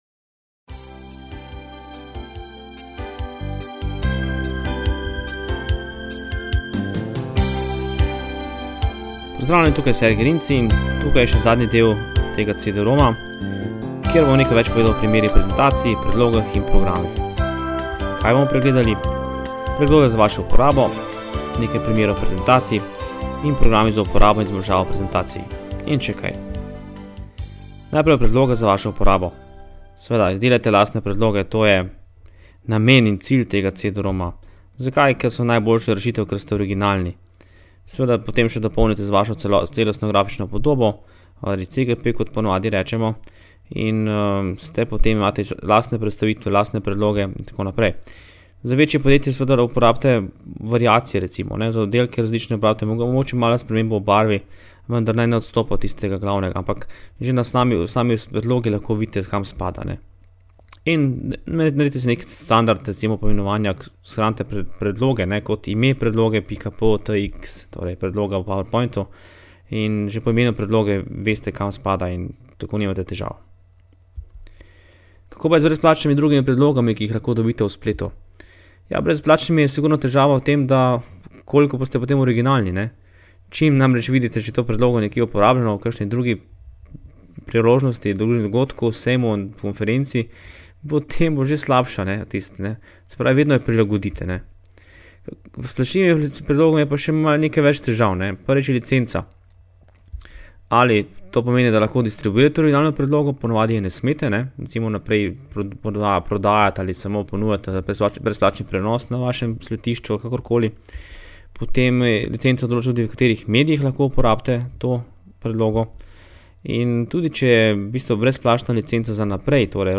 Zvočni posnetek lahko prenesete v predvajalnik MP3 in med pripravo prezentacije poslušate navodila.